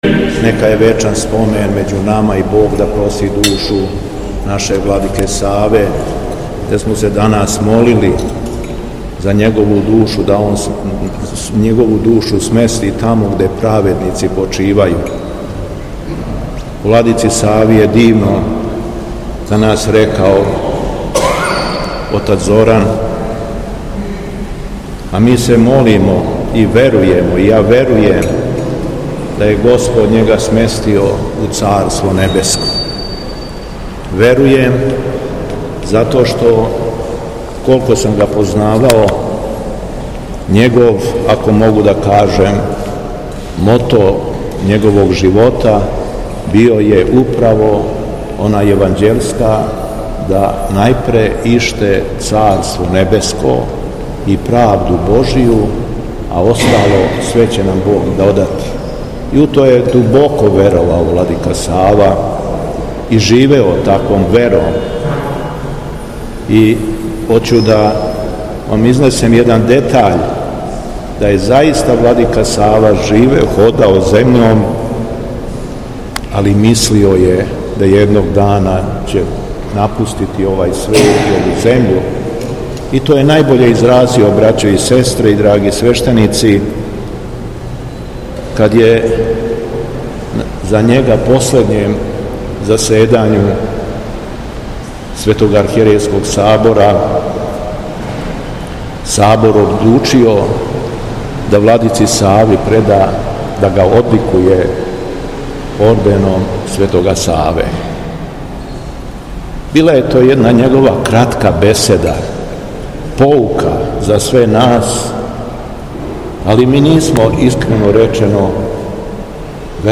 По завршеном помену, Митрополит шумадијски Господин Јован је рекао:
Духовна поука Његовог Високопреосвештенства Митрополита шумадијског г. Јована